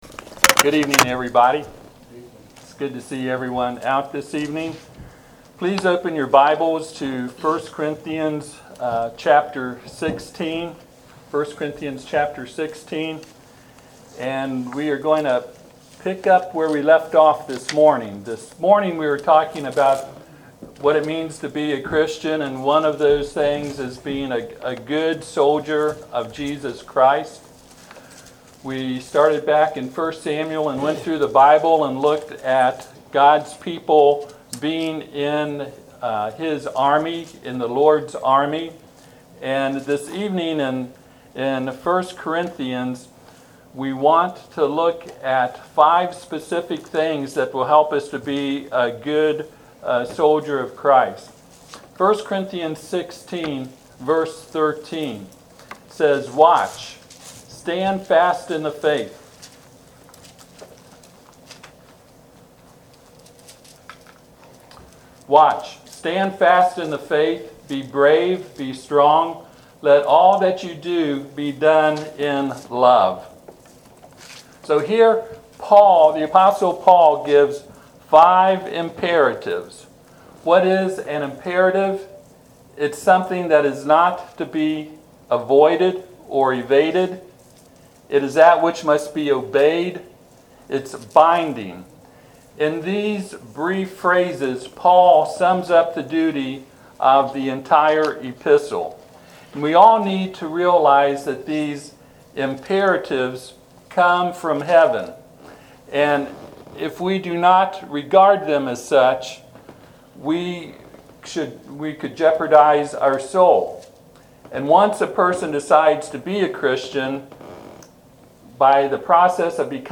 Passage: 1 Corinthians 16:13-14 Service Type: Sunday PM « What is a Christian?